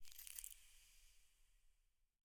eyeblossom_close1.ogg